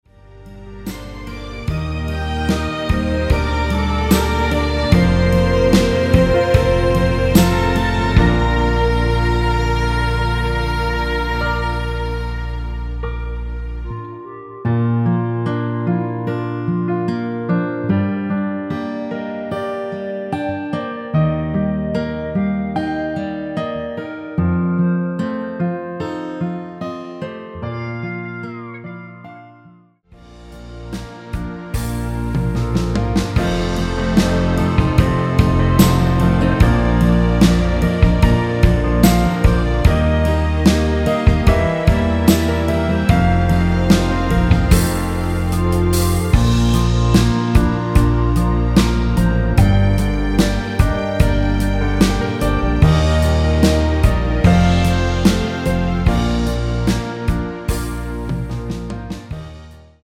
원키에서(-2)내린 멜로디 포함된 MR입니다.
Bb
노래방에서 노래를 부르실때 노래 부분에 가이드 멜로디가 따라 나와서
앞부분30초, 뒷부분30초씩 편집해서 올려 드리고 있습니다.
중간에 음이 끈어지고 다시 나오는 이유는